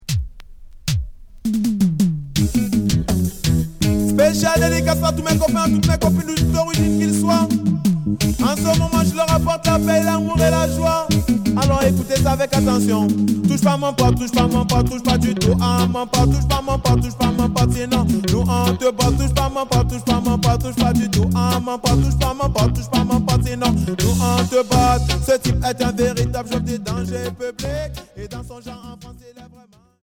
Reggae rub a dub Deuxième 45t retour à l'accueil